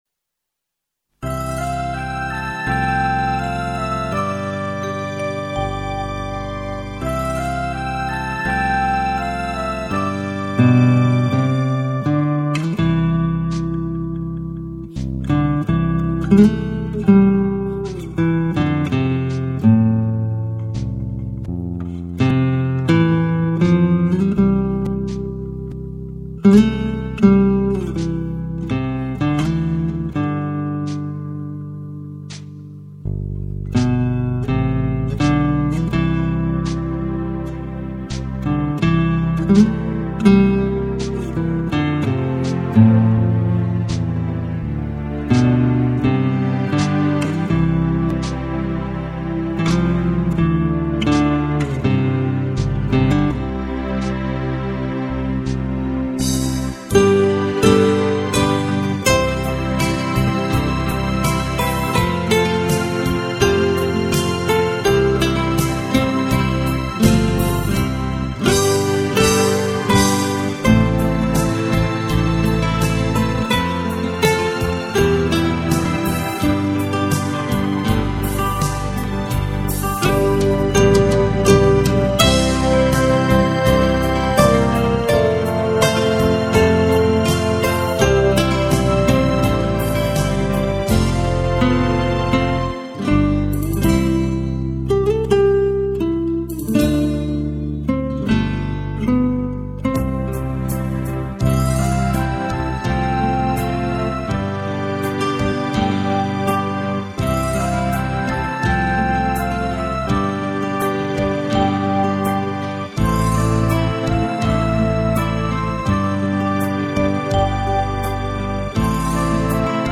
0143-吉他名曲丹尼男孩.mp3